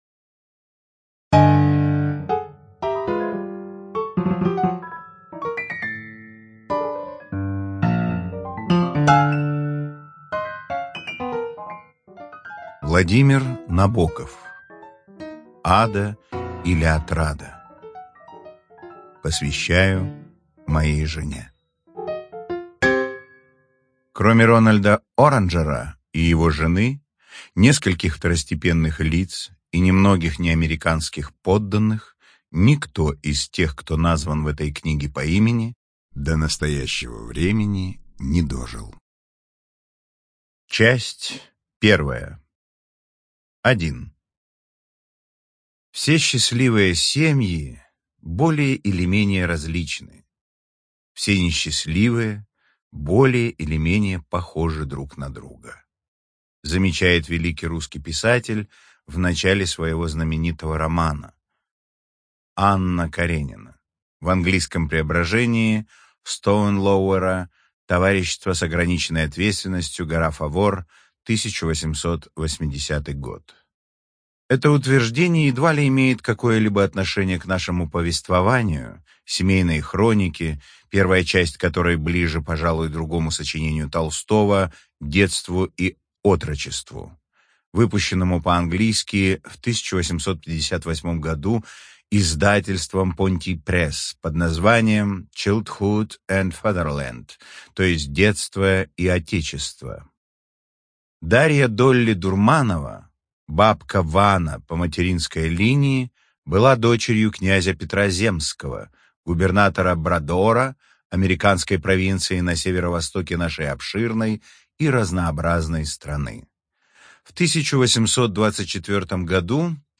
ЖанрКлассическая проза